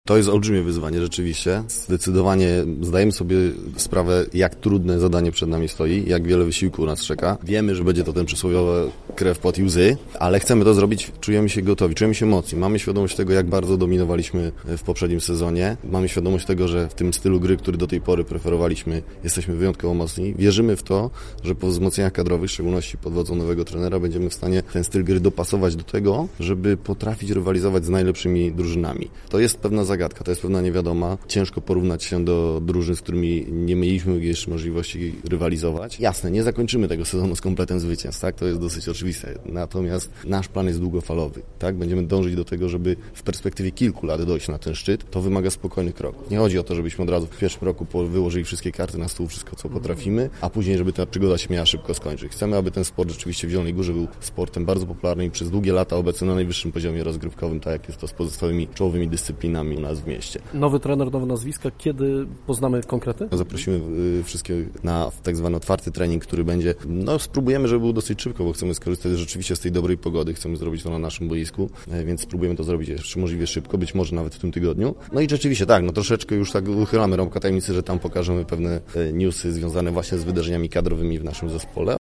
Tę informację przekazano dziennikarzom dziś podczas oficjalnej konferencji prasowej.